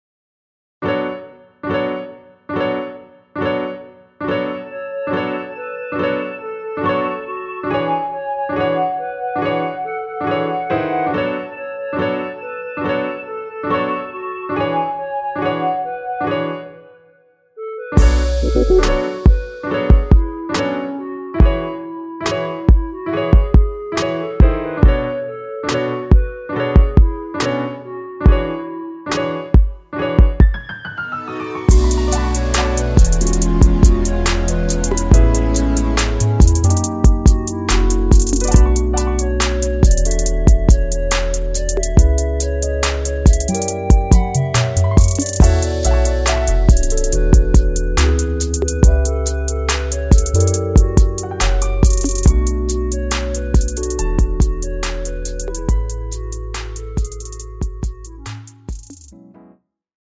원키 멜로디 포함된 MR 입니다.(미리듣기 참조)
앞부분30초, 뒷부분30초씩 편집해서 올려 드리고 있습니다.
중간에 음이 끈어지고 다시 나오는 이유는